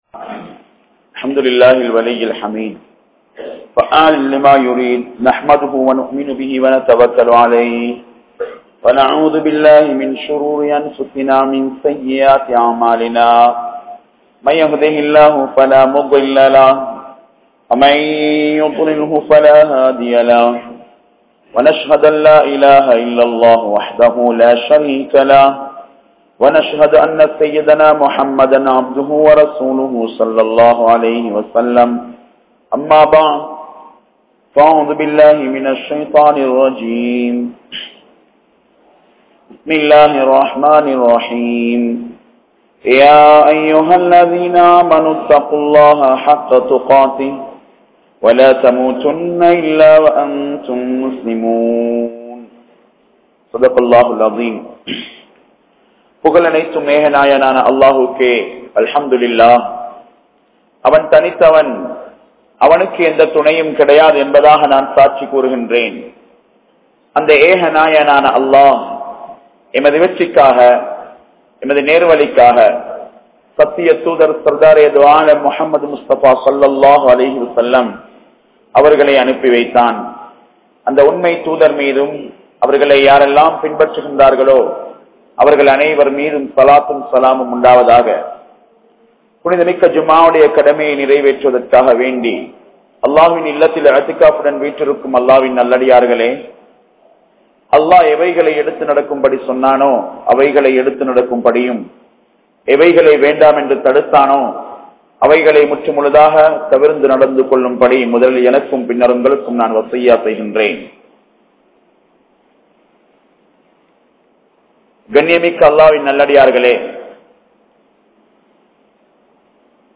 Injustice(அநியாயத்தின் விளைவுகள்) | Audio Bayans | All Ceylon Muslim Youth Community | Addalaichenai
Kandy, Udunuwara Jumuah Masjith